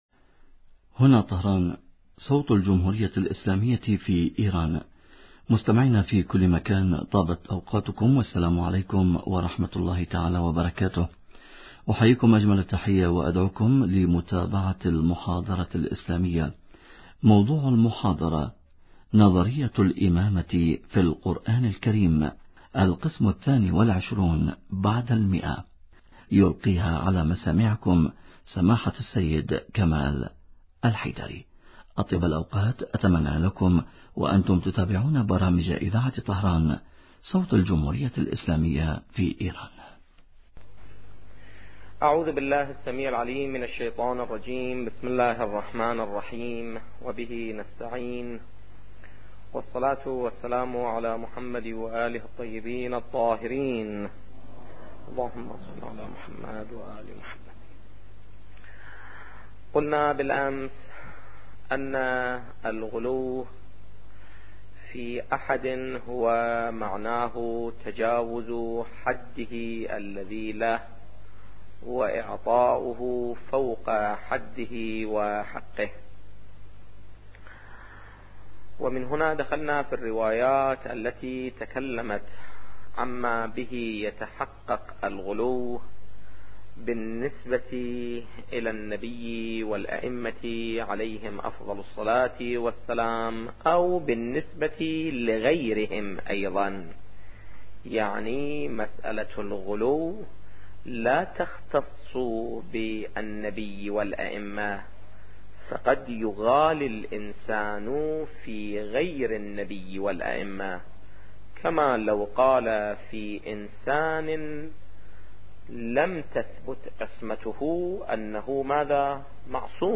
نظرية الامامة في القران الكريم - الدرس الثاني والعشرون بعد المئة